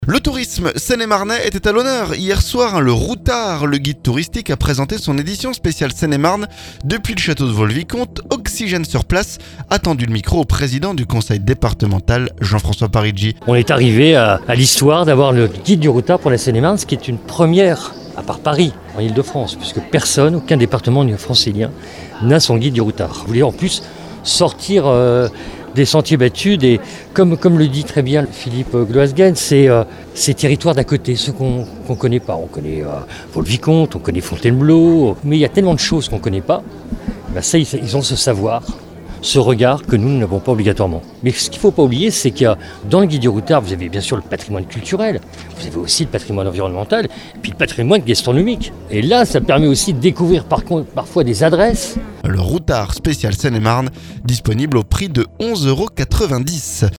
Le Routard, le guide touristique, a présenté son édition spéciale Seine-et-Marne depuis le château de Vaux-le-Vicomte. Oxygène sur place a tendu le micro au président du conseil départemental, Jean-François Parigi.